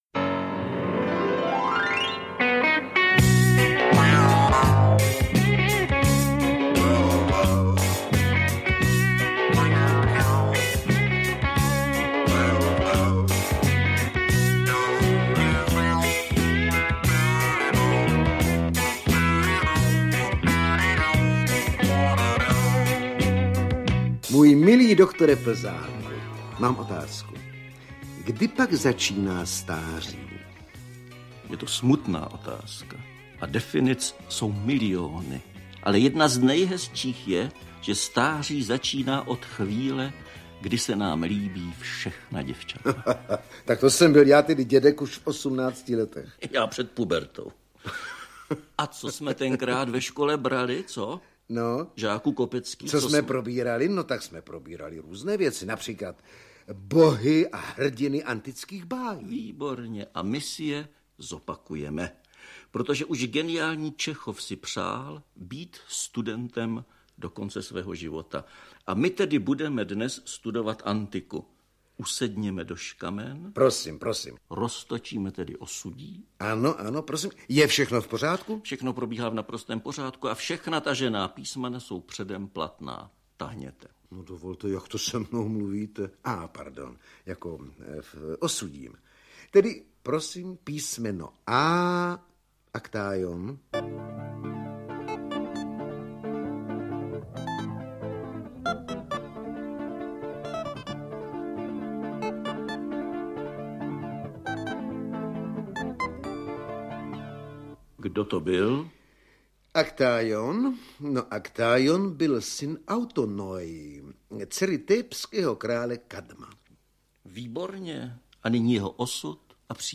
Dva na Olympu audiokniha
Ukázka z knihy
dva-na-olympu-audiokniha